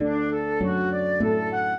flute-harp
minuet0-12.wav